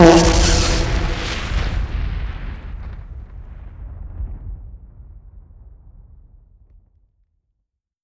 sentry_explode.wav